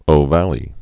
(ō-vălē, -vālē, -vä-)